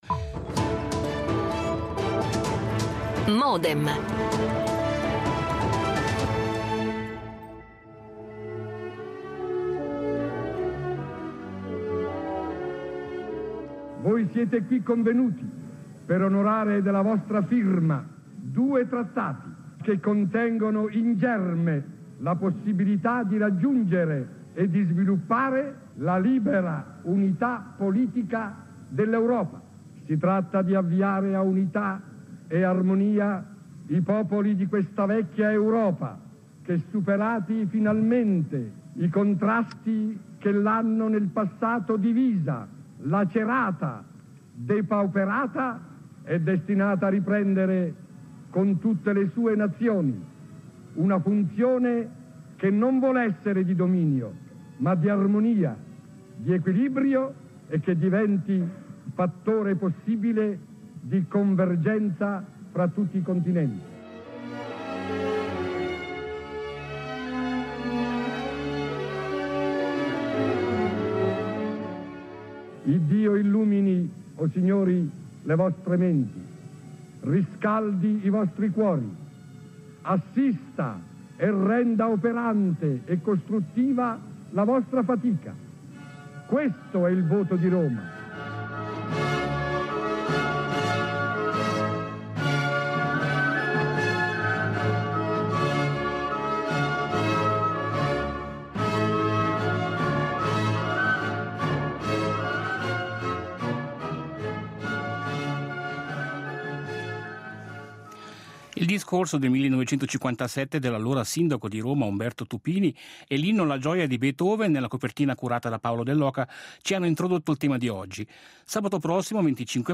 Con la partecipazione dell'ex senatore Dick Marty, del finanziere Tito Tettamanti e di Romano Prodi, già presidente della Commissione Europea.